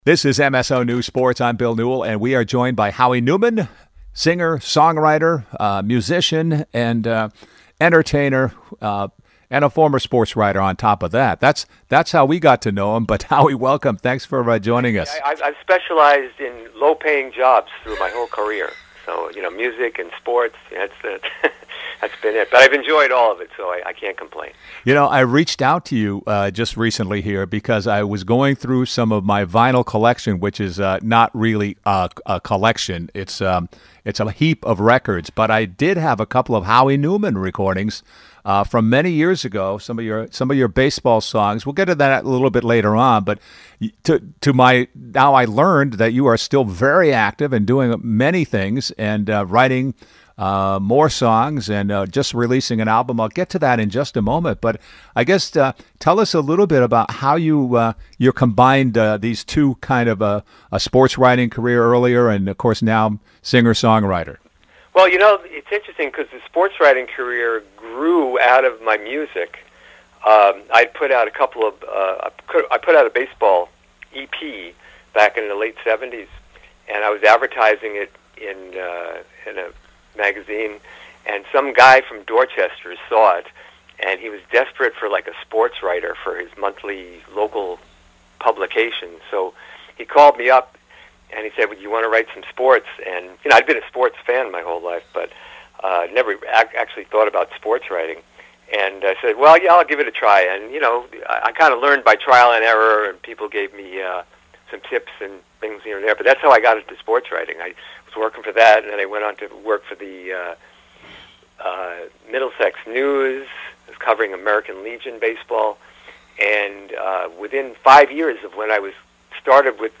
Interview & Music